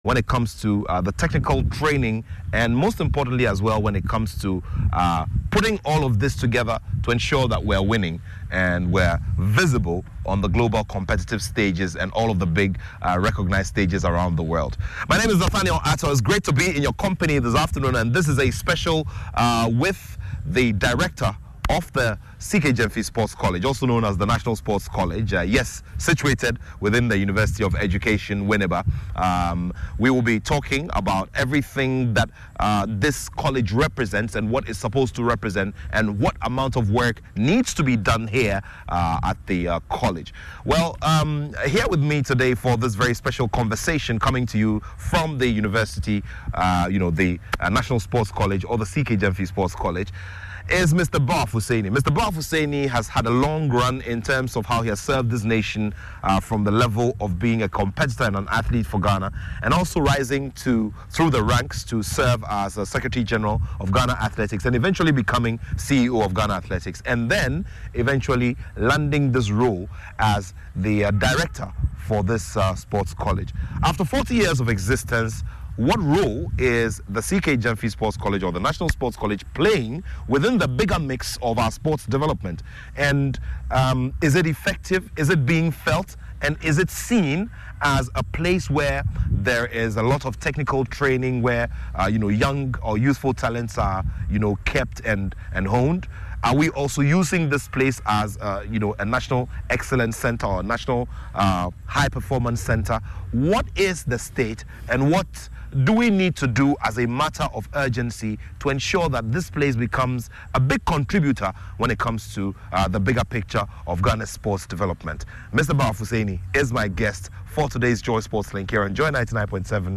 A sports discussion show on big-name personalities and key issues in sports